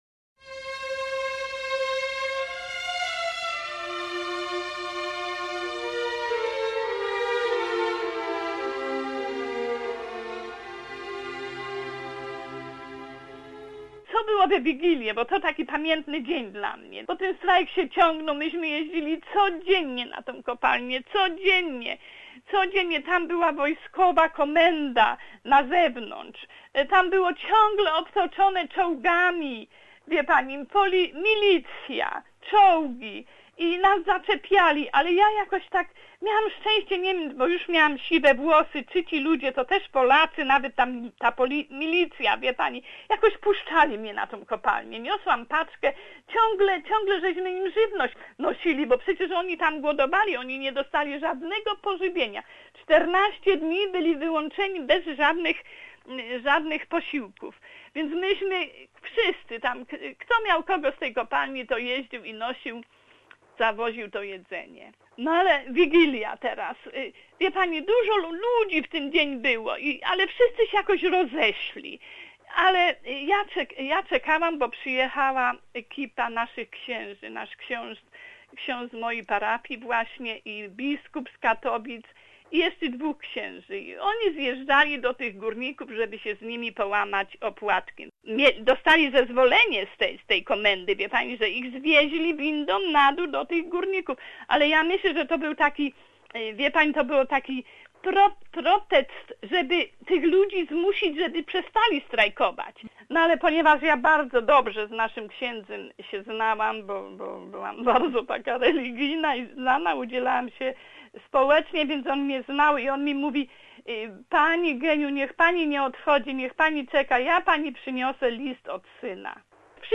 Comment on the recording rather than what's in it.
Part of the archival recording of the story about the first Christmas Eve during martial law in Poland.